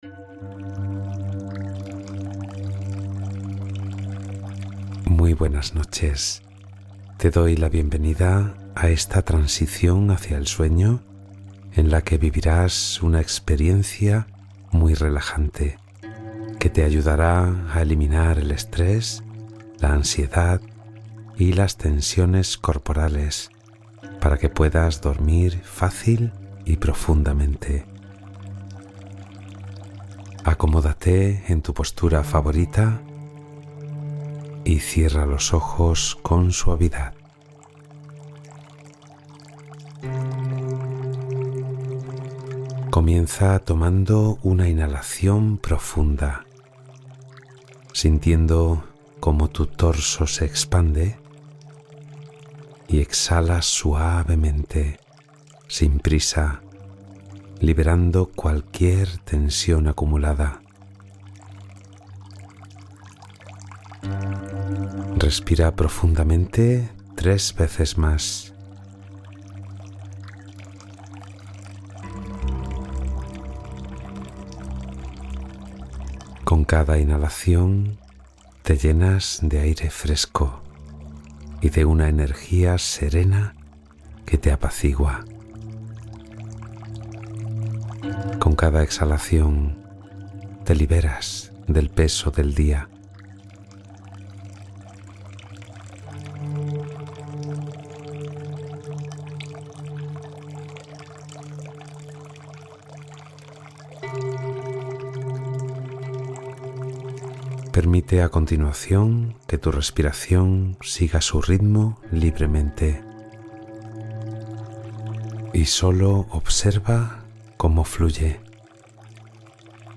Meditación guiada y vivencial para dormir profundamente y despertar renovado